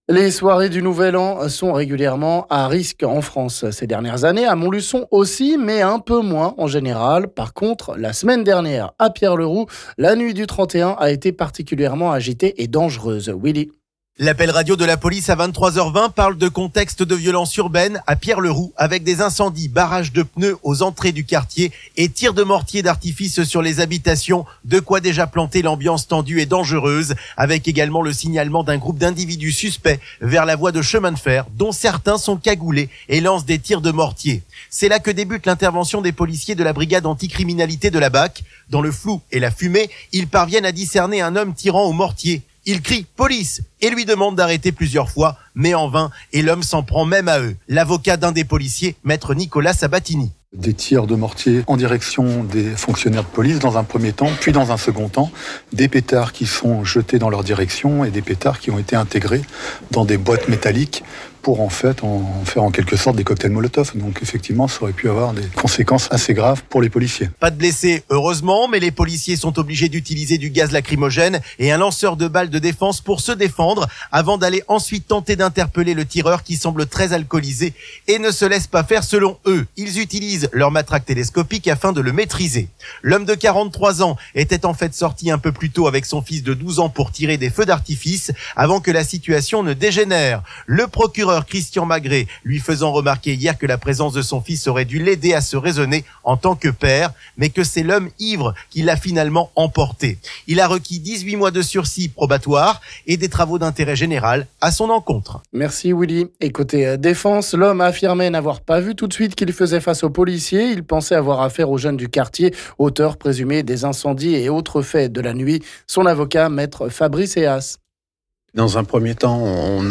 Sujet à écouter ici avec les avocats des 2 parties...